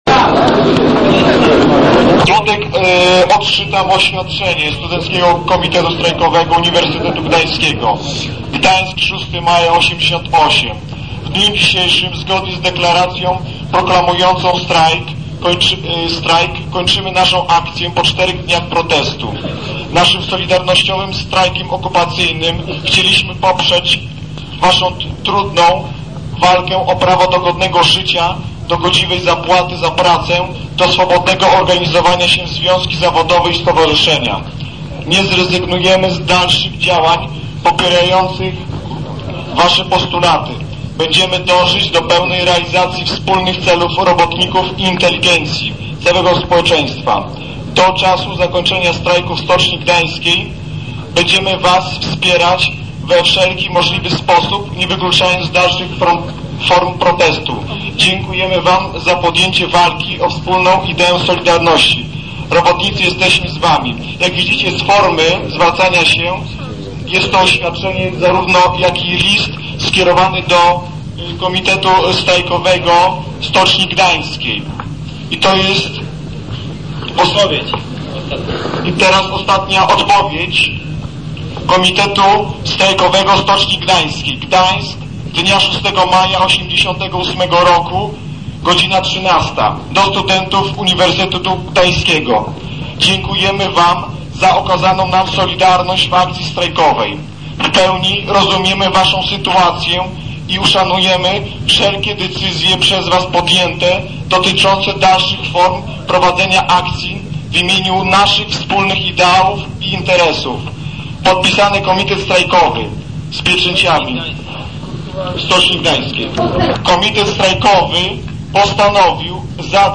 Podczas ostatniego dnia tzw. strajku sierpniowego w Stoczni Gdańskiej. Trwał wiec przy bramie stoczni i Pomniku Poległych Stoczniowców.
strajk.mp3